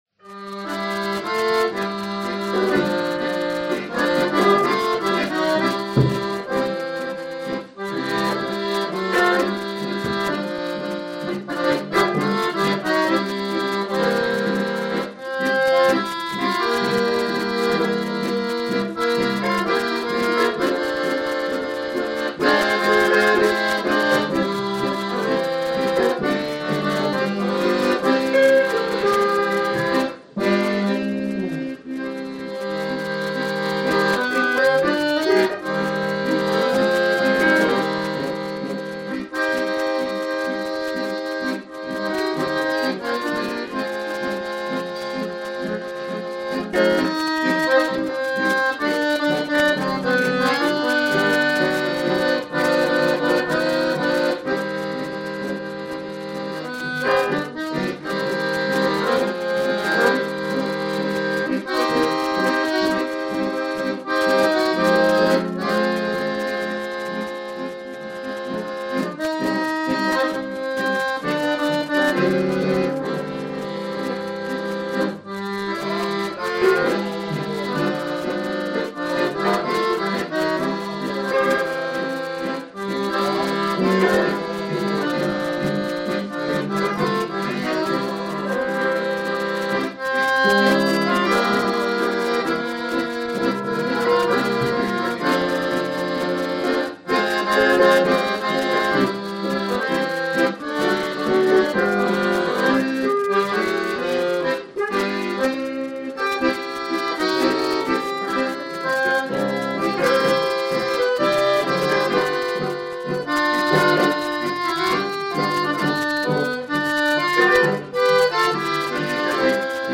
Her har vi et amatøropptak
Varierende og allsidig fest og danse -musikk.